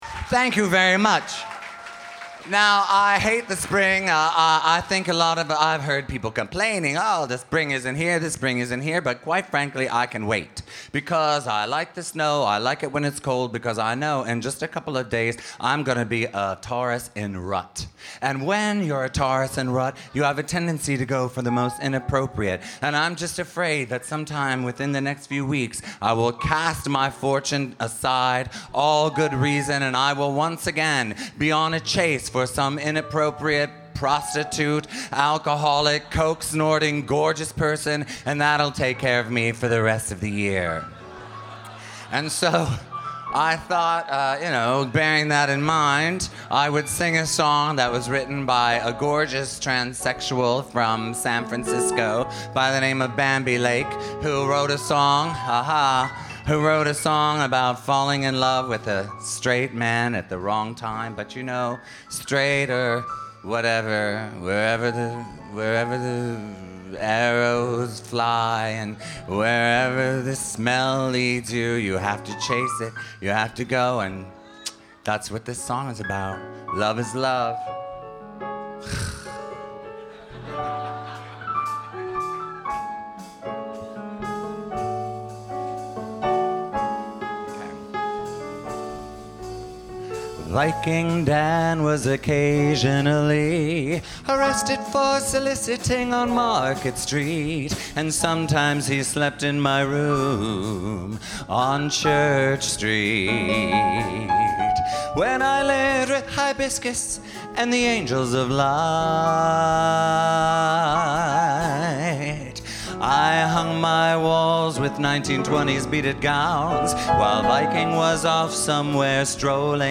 April 8, 2007 at The Box
Performed by Justin Bond.